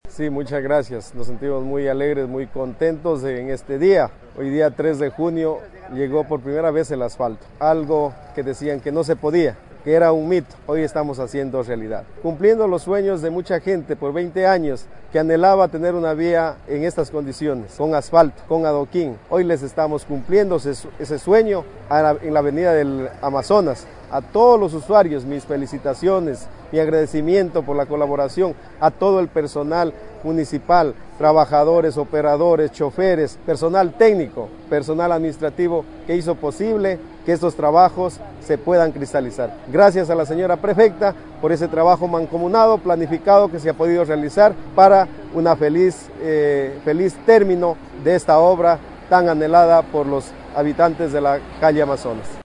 HENRY ORDOÑEZ, ALCALDE DE CHINCHIPE